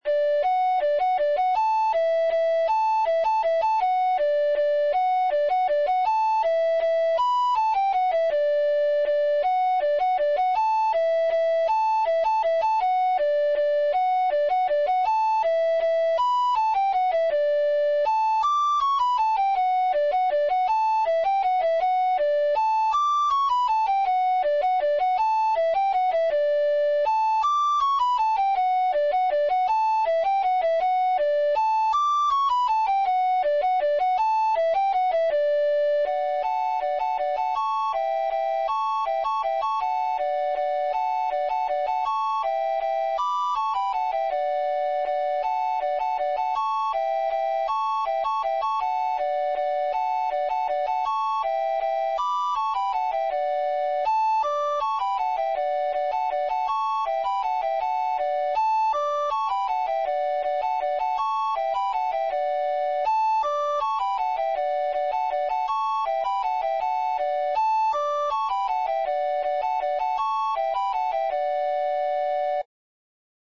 Mazurcas – Pezas para Gaita Galega
Dúo
mazurca-da-bretonha-duo.mp3